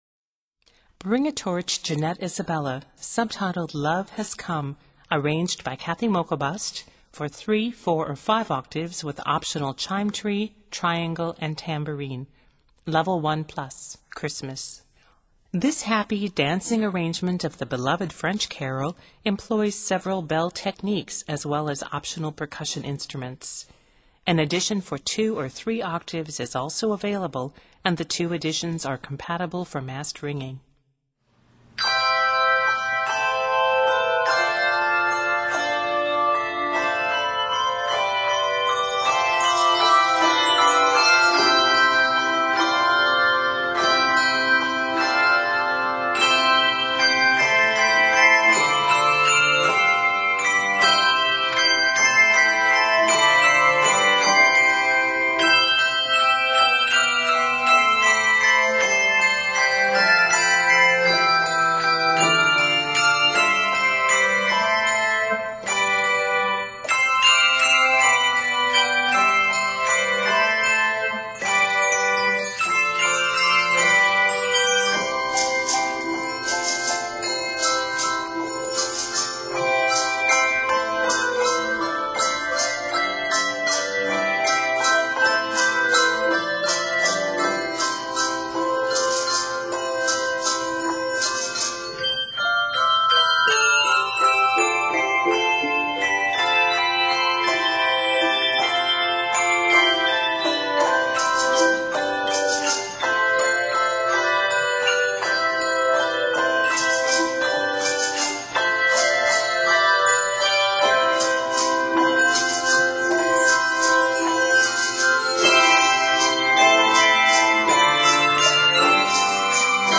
This rendition is set in C Major and is 110 measures.